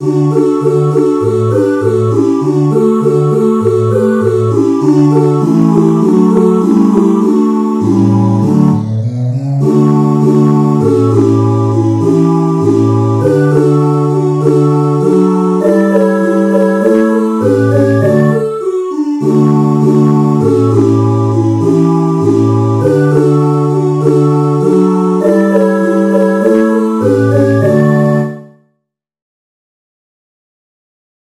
Jewish Folk Song for Pesach (Passover)
Choral Arrangement
D major ♩= 100 bpm
niggun_023a_dayenu_choral_01.mp3